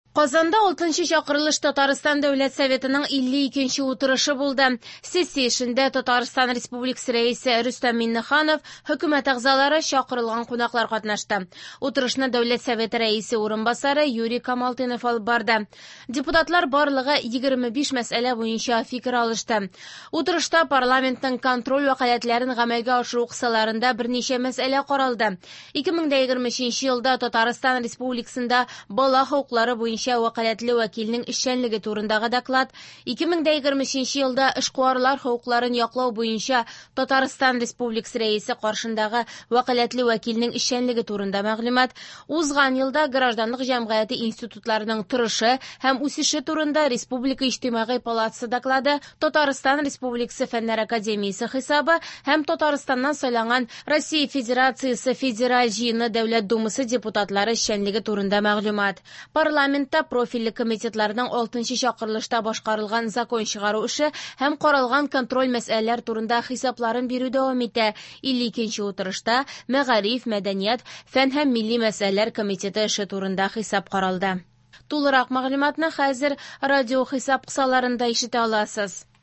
Радиоотчет (29.03.24) | Вести Татарстан
В эфире специальный информационный выпуск, посвященный 52 заседанию Государственного Совета Республики Татарстан 6-го созыва.